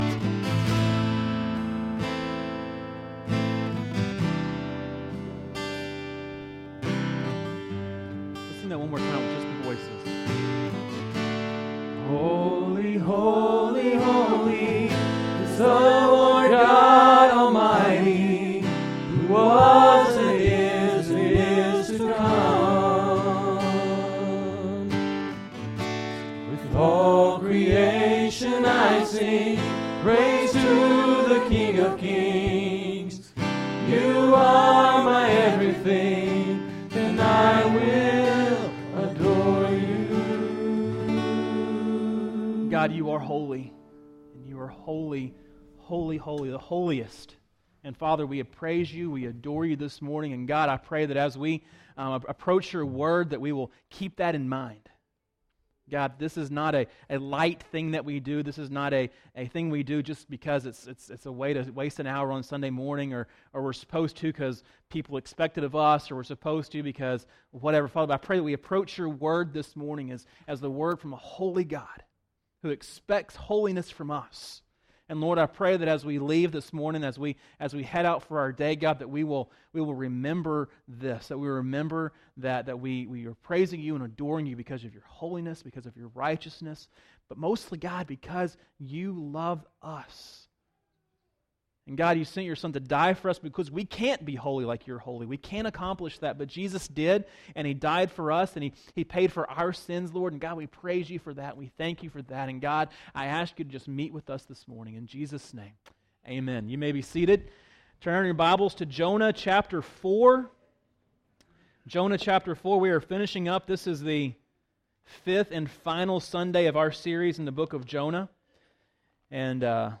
Sermons - First Baptist Church Corning